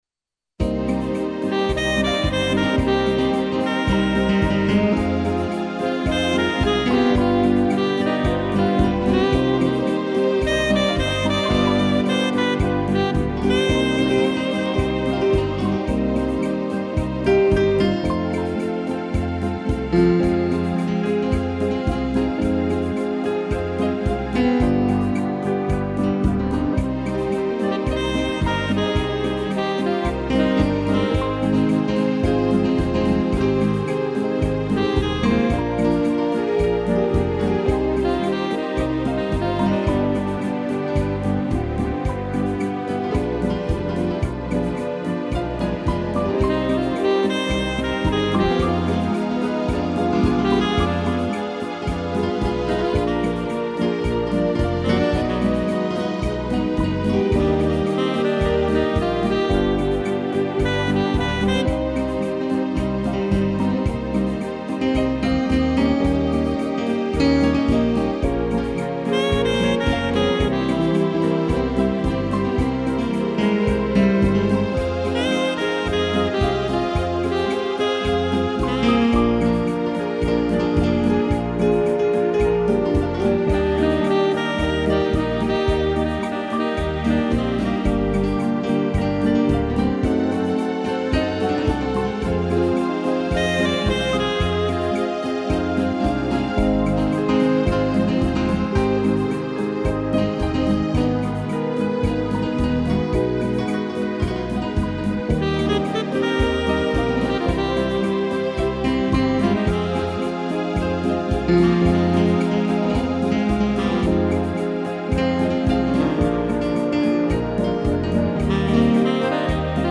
piano, sax e tutti
(instrumental)